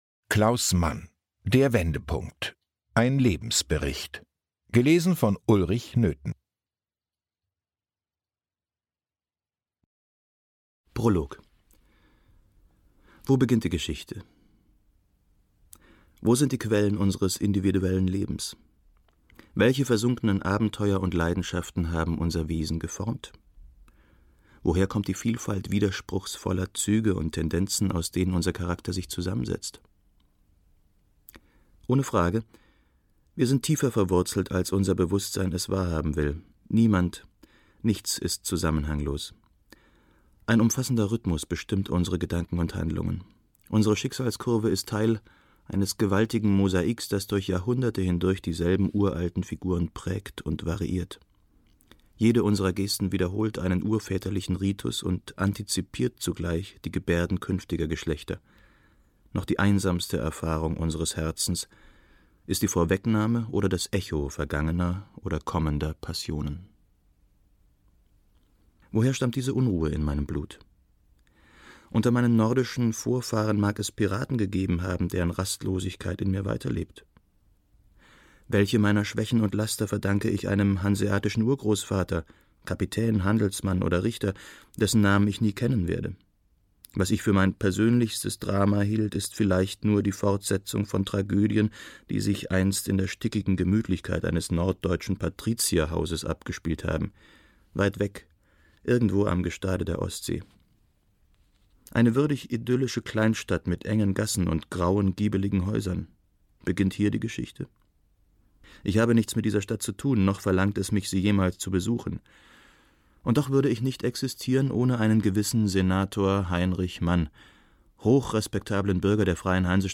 Der Wendepunkt. Ein Lebensbericht - Klaus Mann - Hörbuch